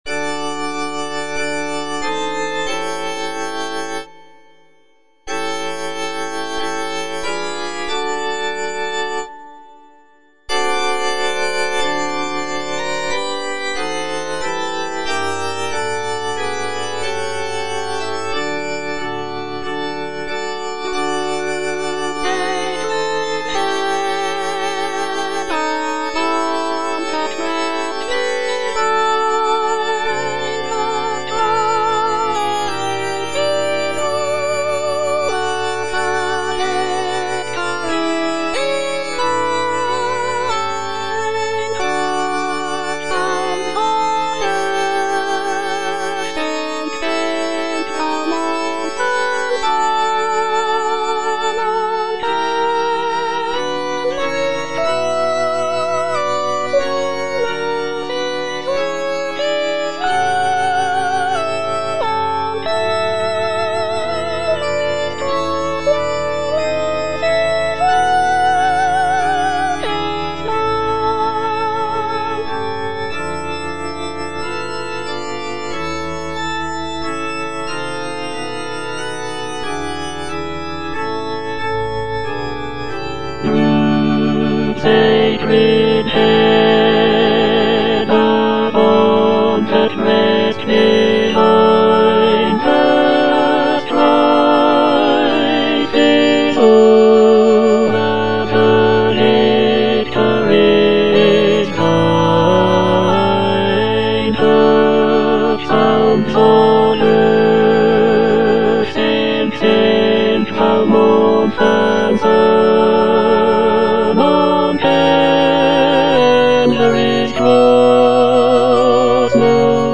J.H. MAUNDER - OLIVET TO CALVARY 10. Calvary - Tenor (Emphasised voice and other voices) Ads stop:
"Olivet to Calvary" is a sacred cantata composed by John Henry Maunder in 1904.
"Olivet to Calvary" is known for its lyrical melodies, dramatic choral writing, and poignant orchestration.